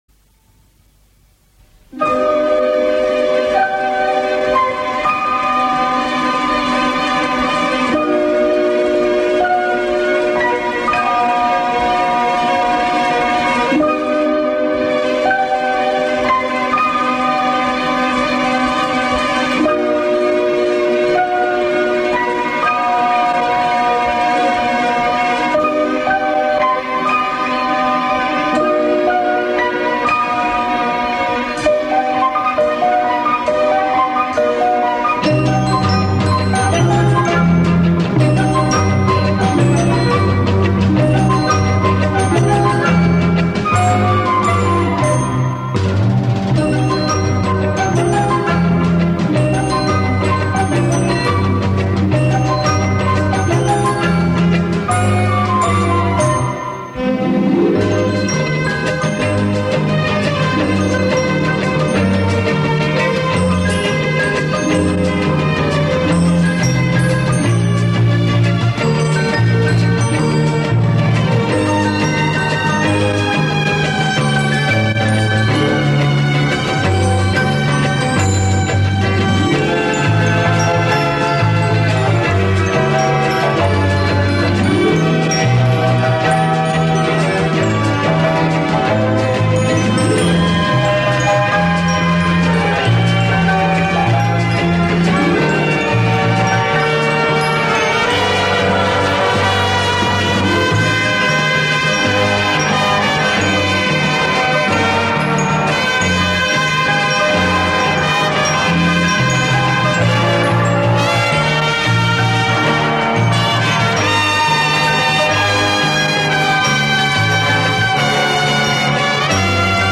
Очень красивая мелодия! broken heart